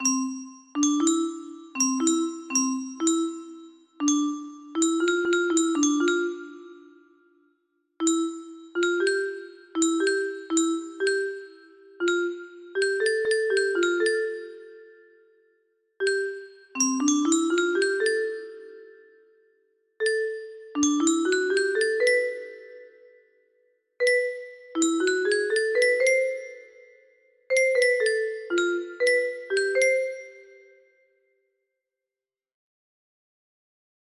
31006 music box melody